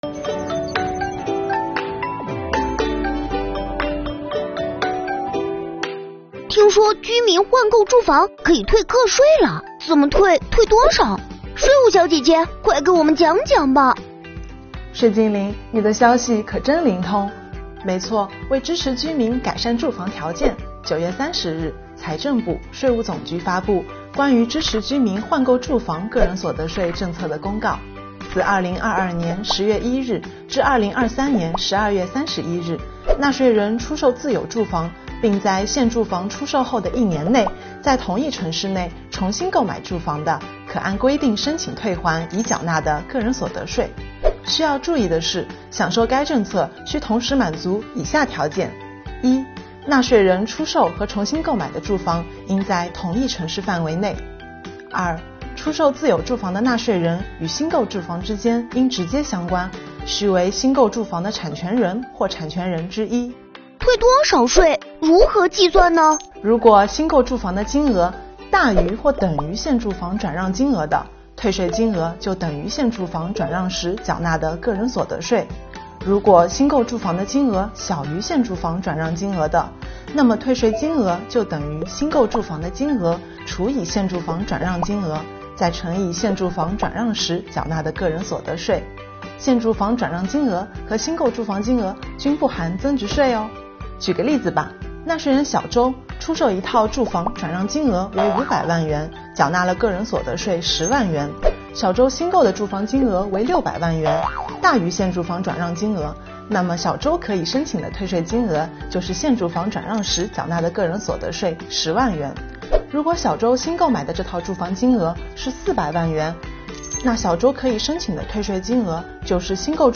为支持居民改善住房条件，财政部、税务总局发布《关于支持居民换购住房有关个人所得税政策的公告》。今天邀请到了税务小姐姐给大家讲讲政策的具体内容，一起来看看吧~